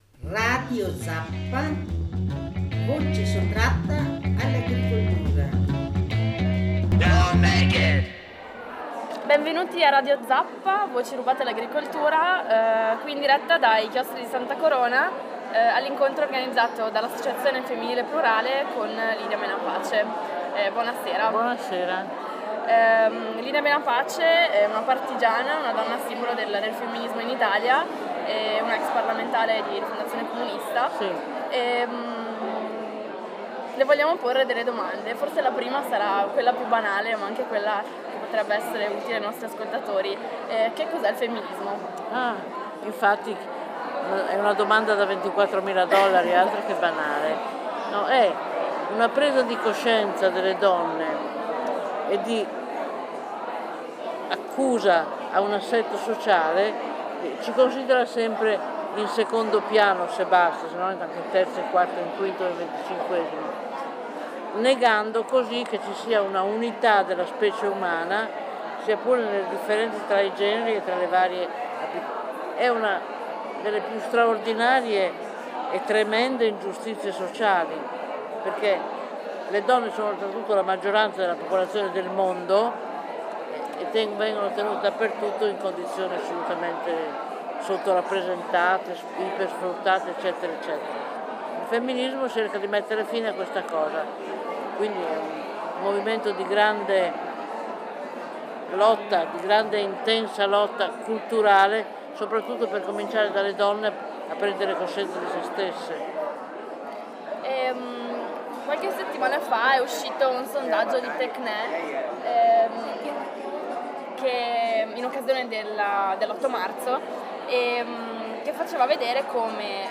Intervista a Lidia Menapace, partigiana, donna simbolo del movimento femminista in Italia, ex parlamentare di Rifondazione Comunista e pacifista.
Abbiamo intervistato Lidia Menapace, partigiana, donna simbolo del movimento femminista in Italia, ex parlamentare di Rifondazione Comunista e pacifista, in occasione dell’incontro organizzato dall’Associazione Femminile Plurale ai Chiostri di Santa Corona a Vicenza. Le abbiamo chiesto cosa vuol dire essere femministe oggi e come poter colmare le disparità tra uomini e donne in Italia. Ci ha infine raccontato la sua esperienza di donna partigiana e spiegato come si possono attuare i valori della resistenza anche oggi.